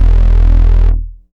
72.07 BASS.wav